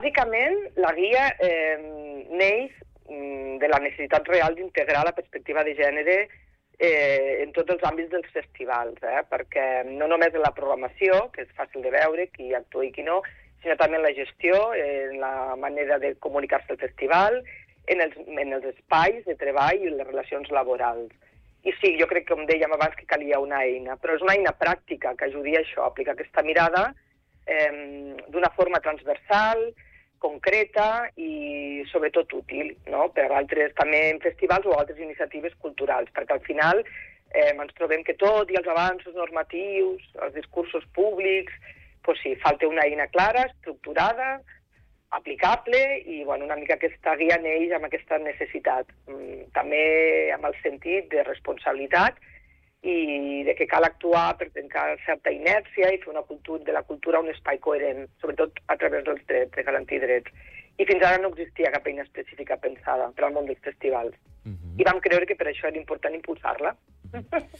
Entrevistes